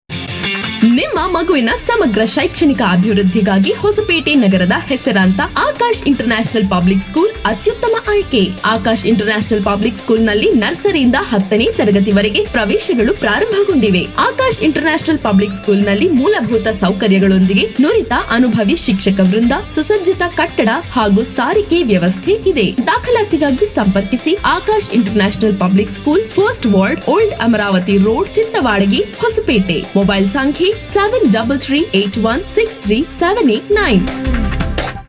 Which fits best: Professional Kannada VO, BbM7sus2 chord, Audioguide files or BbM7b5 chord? Professional Kannada VO